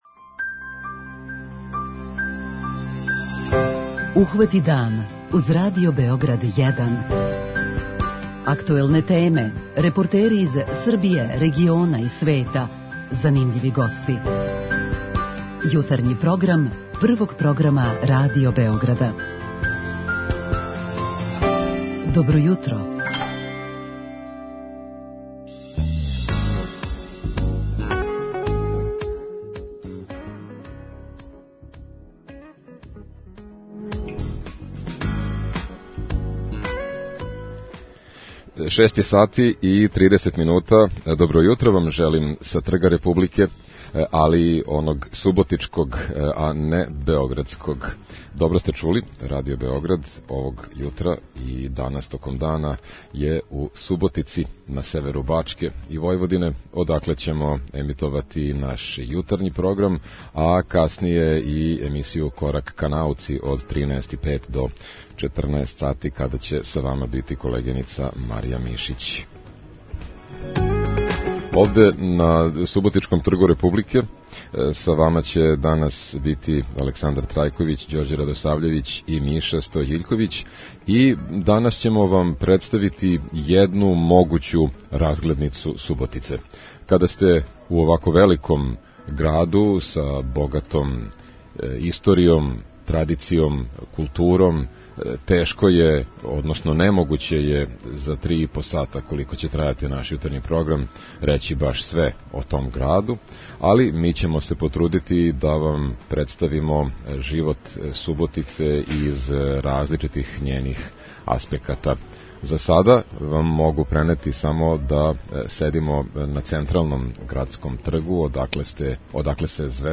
Овога јутра Радио Београд је на самом северу Бачке. Будимо вас из центра Суботице с погледом на чувену Градску кућу и Плаву фонтану.